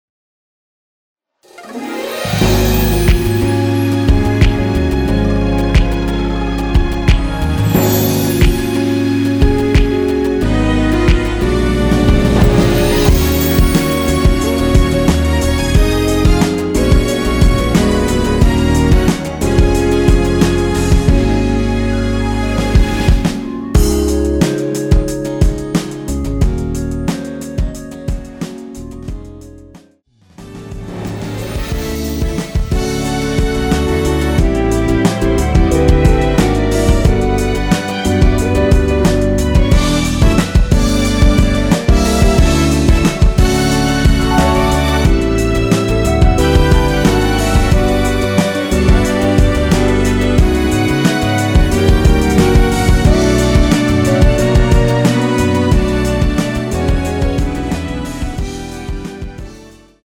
원키에서(-2) 내린 멜로디 포함된 MR입니다.
Db
앞부분30초, 뒷부분30초씩 편집해서 올려 드리고 있습니다.